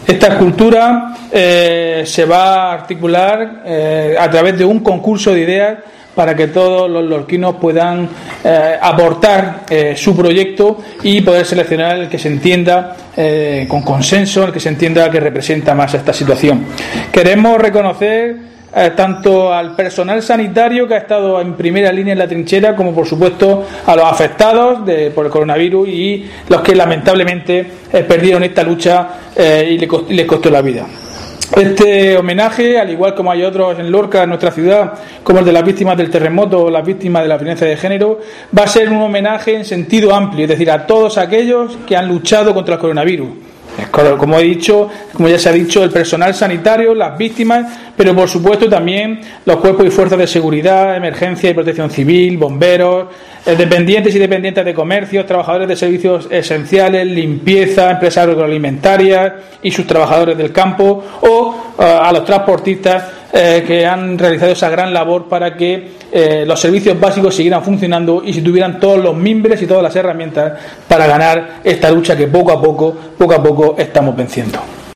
Diego José Mateos, alcalde de Lorca sobre escultura